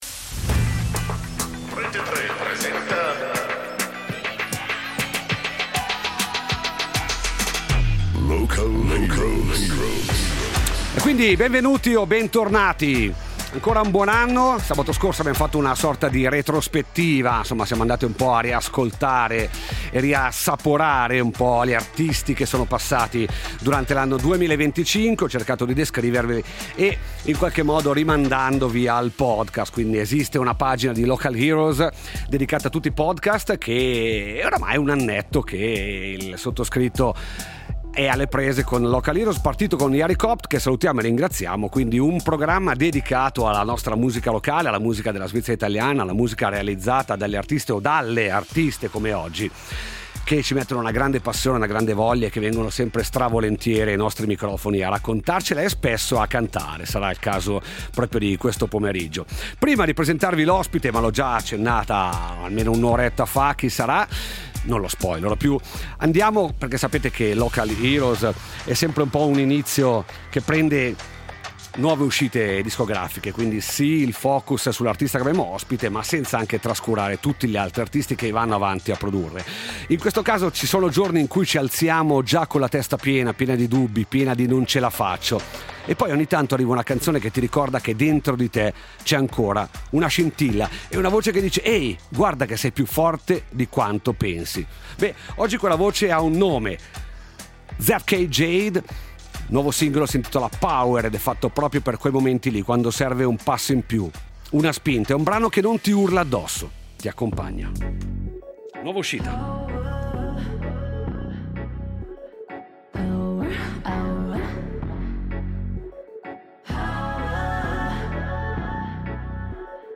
Storie, musica e un po’ di magia live!